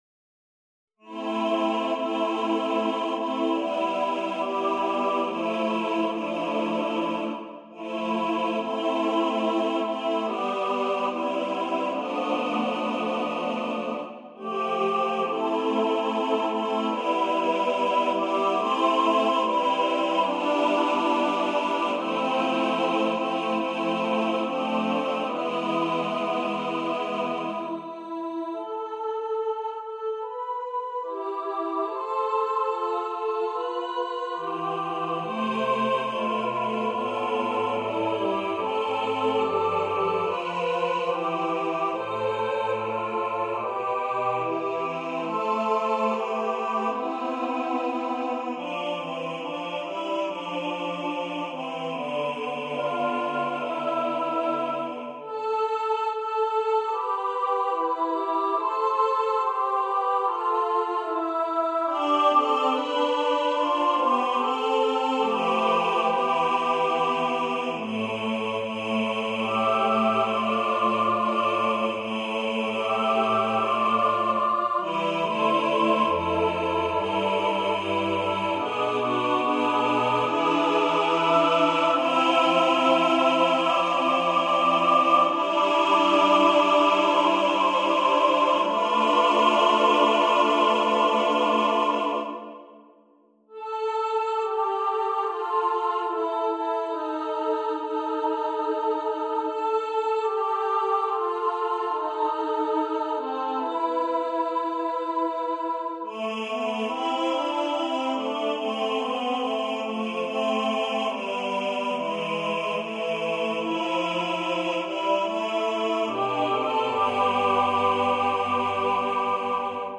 SATB, a capella
Audi Only (Higher Quality)